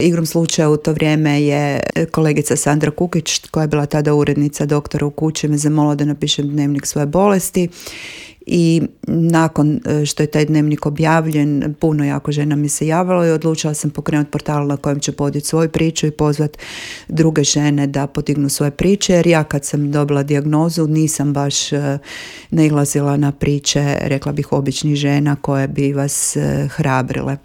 gostovala je u Intervjuu Media servisa u kojem je govorila o svemu što stoji iza ove prestižne nagrade te najpoznatijim projektima udruge